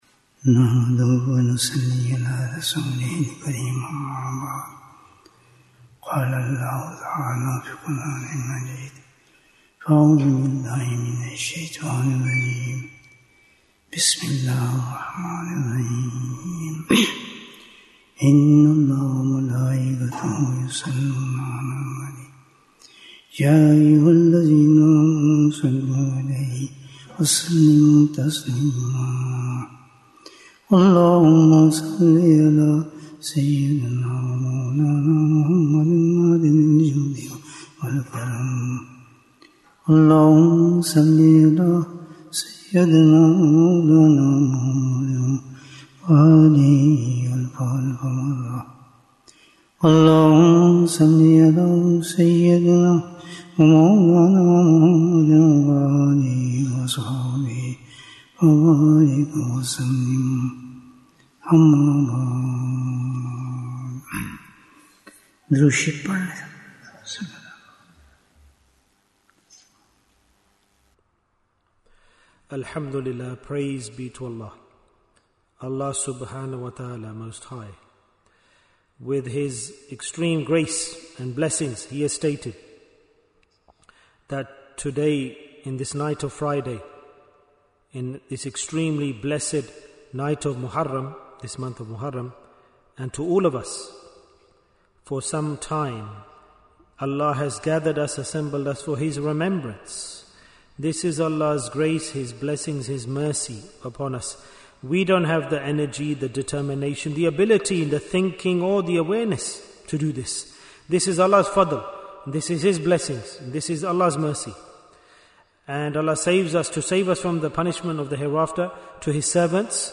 What is the Most Dangerous Thing We Must Save Ourselves From? Bayan, 45 minutes3rd July, 2025